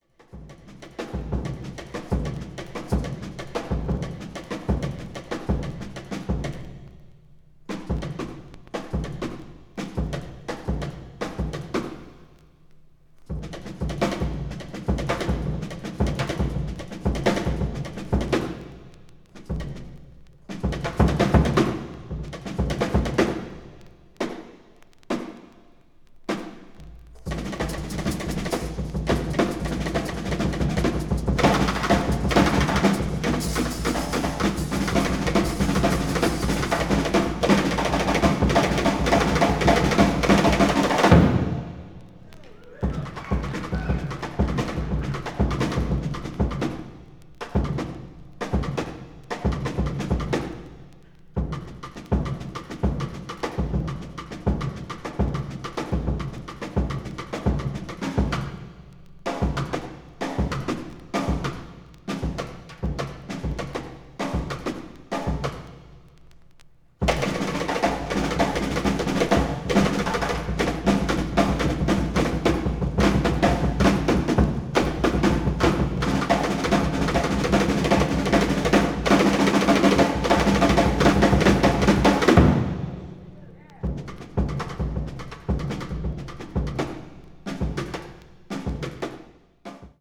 1979年にニューヨークのコロンビア大学内にあるMc Millin Theatreで行ったライヴの音源を収録。
凄まじいまでにパワフルなドラミング。
avant-jazz   free improvisation   free jazz